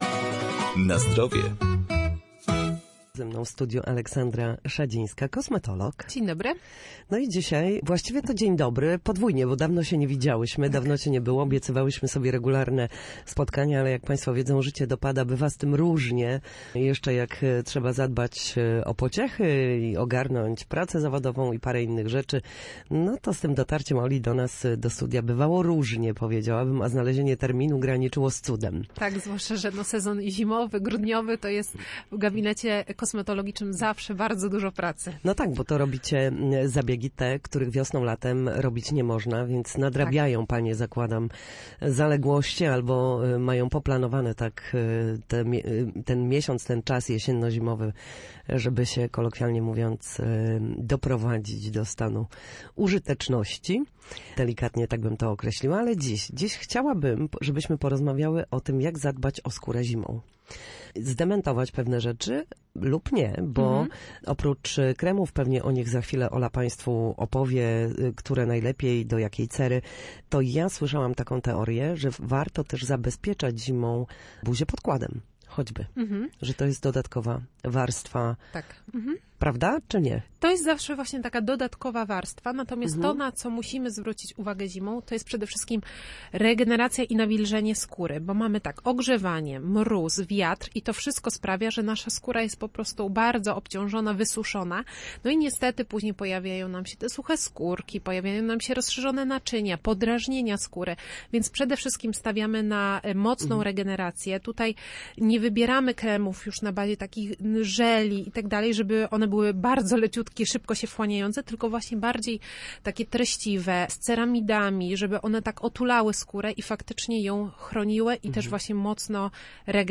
W każdą środę, w popołudniowym Studiu Słupsk Radia Gdańsk dyskutujemy o tym, jak wrócić do formy po chorobach i urazach.